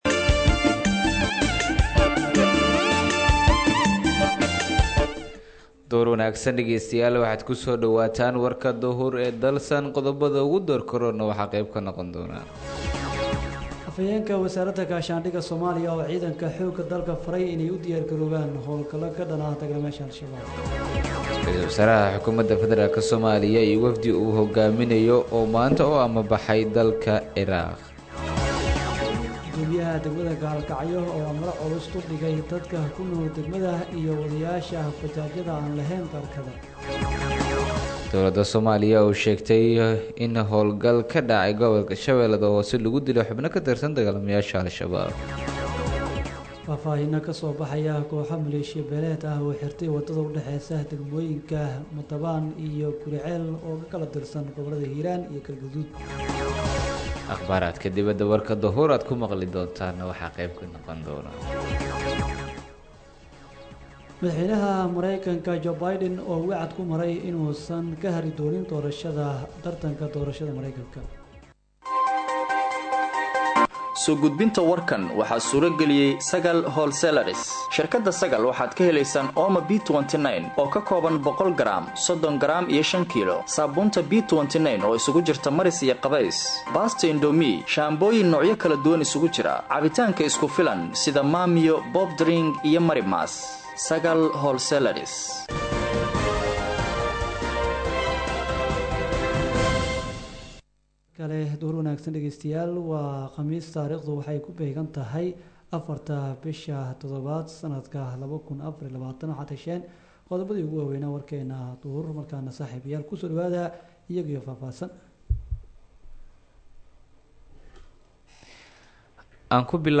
Dhageyso:-Warka Duhurnimo Ee Radio Dalsan 04/07/2024